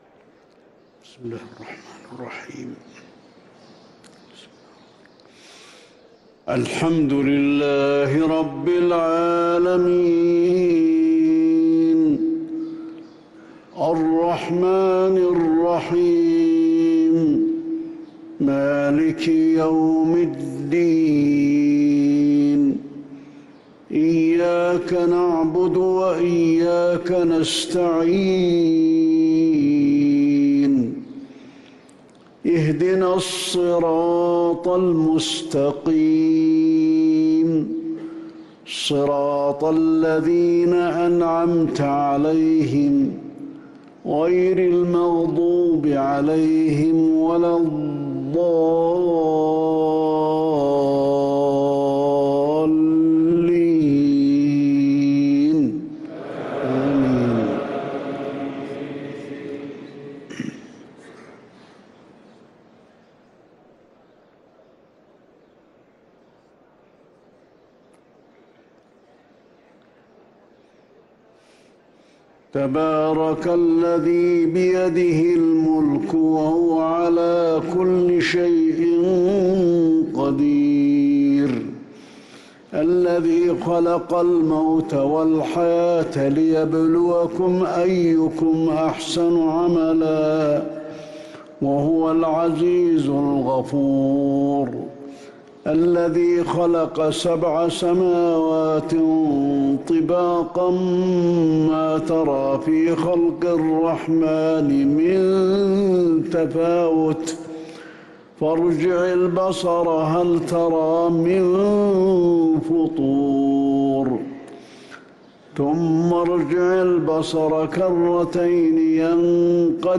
صلاة الفجر للقارئ علي الحذيفي 26 ربيع الآخر 1445 هـ
تِلَاوَات الْحَرَمَيْن .